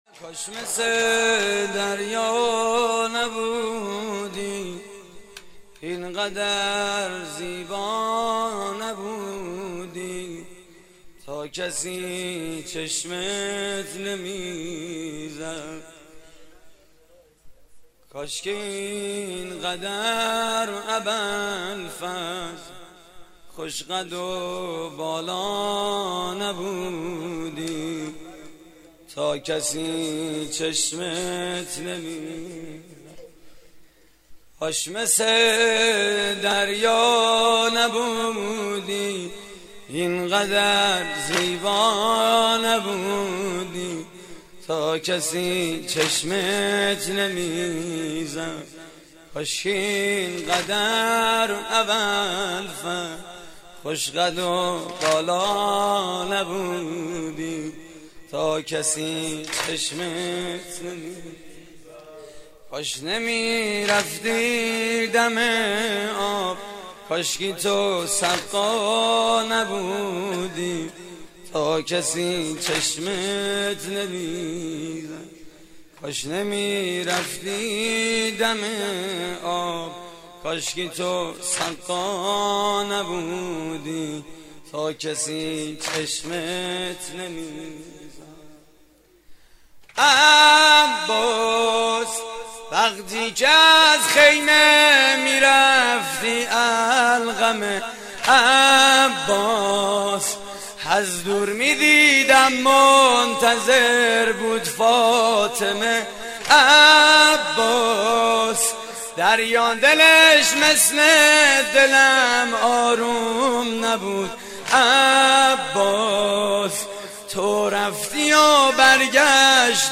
مراسم عزاداری شب تاسوعای حسینی (محرم 1432)